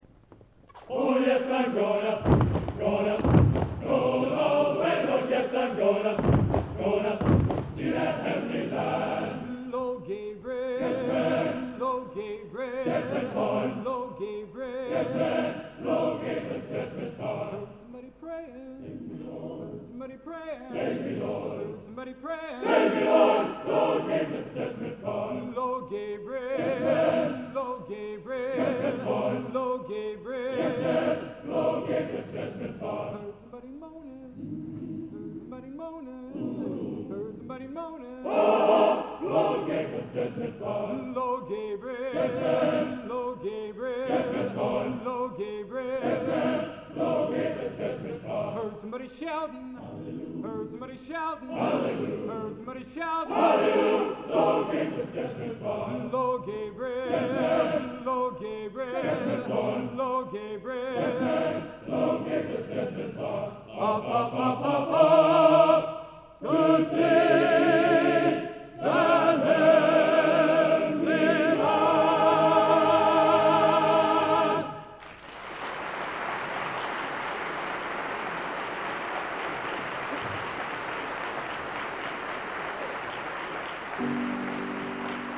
Collection: Plymouth, England